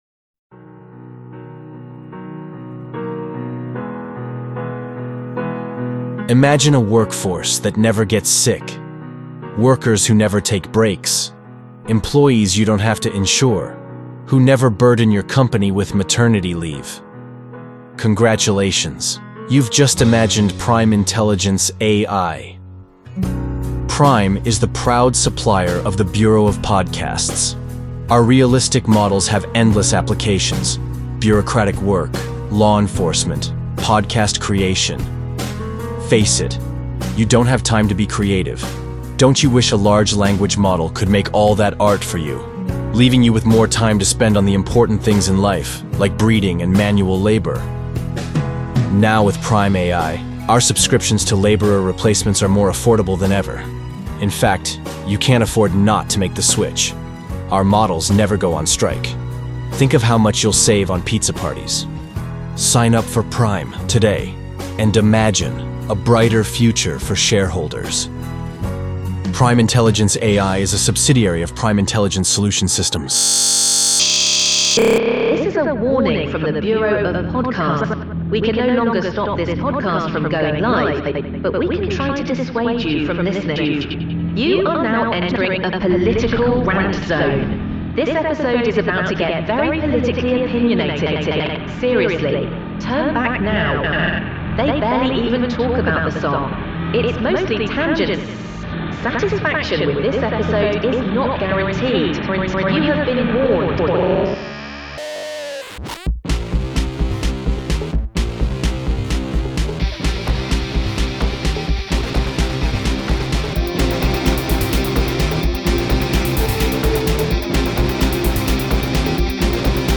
[RANT ZONE!]
Plus some fun mashups.